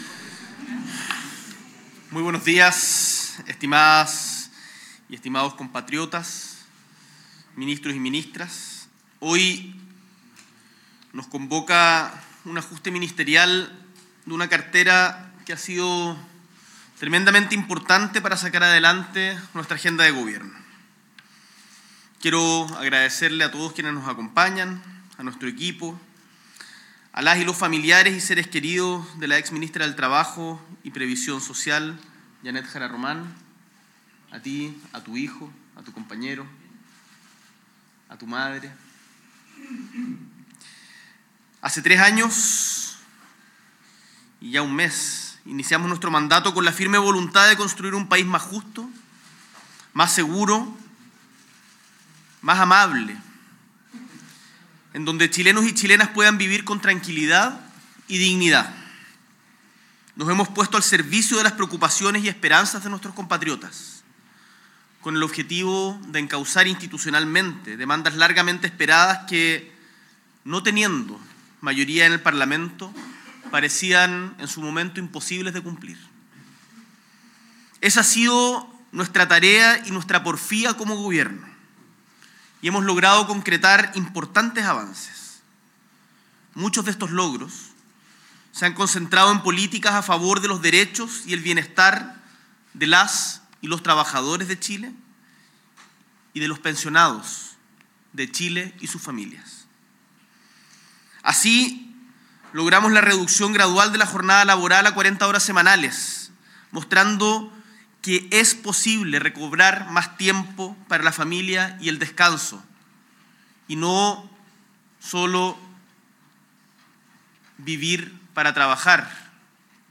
S.E. el Presidente de la República, Gabriel Boric Font, realiza ajuste ministerial
Discurso